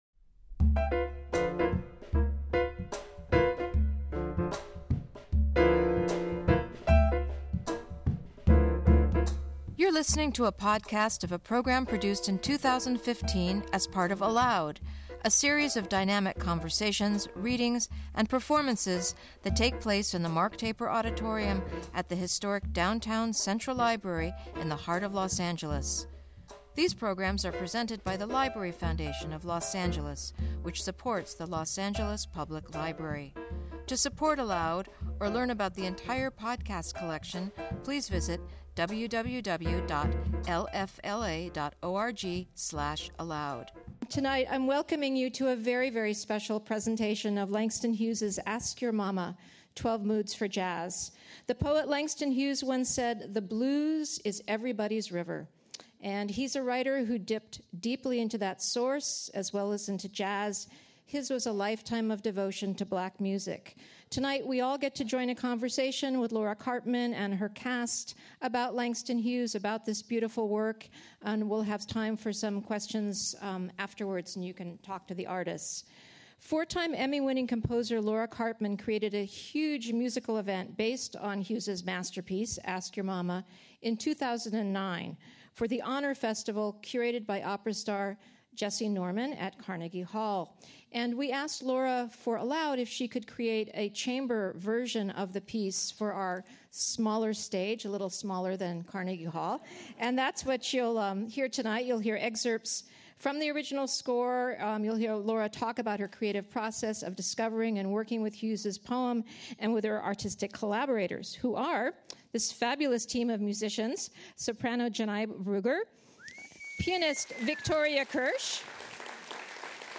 soprano
piano
bass